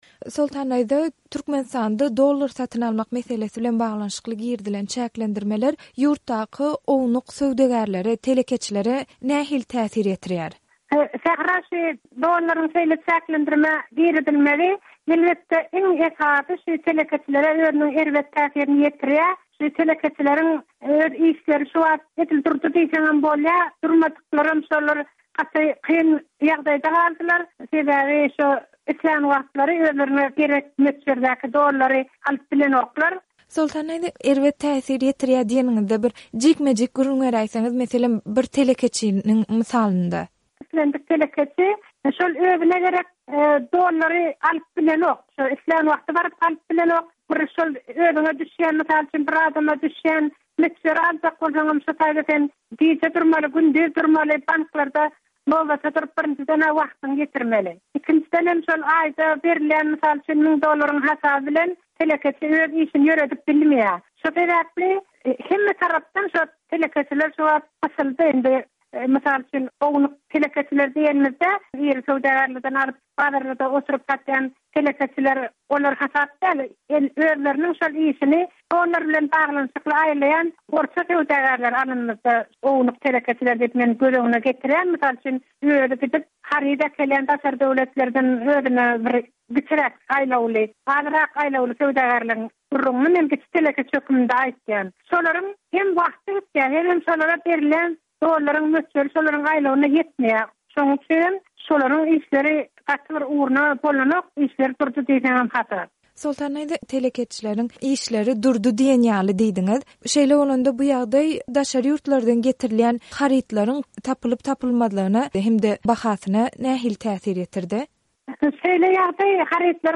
by Azatlyk Radiosy